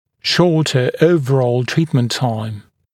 [‘ʃɔːtə ‘əuvərɔːl ‘triːtmənt taɪm][‘шо:тэ ‘оувэро:л ‘три:тмэнт тайм]уменьшение общего времени лечения